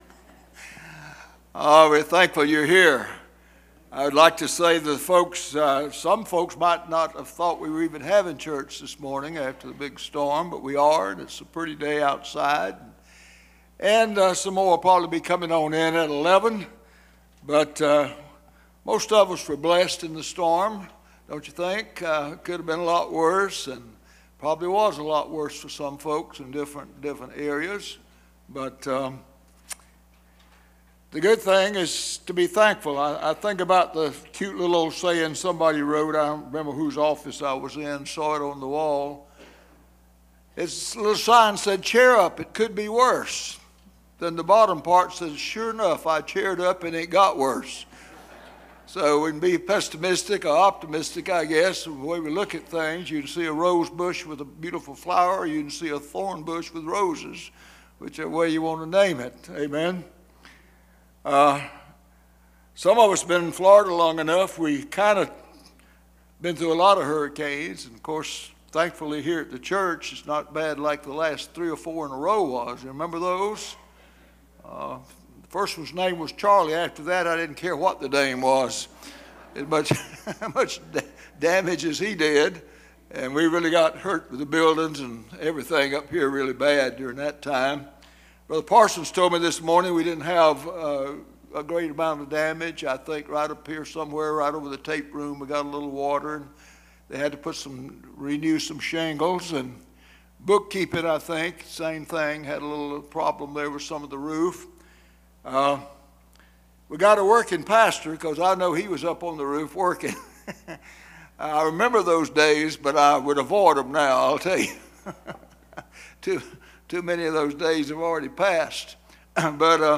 Preacher